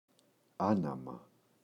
ανάμα, το [a’nama]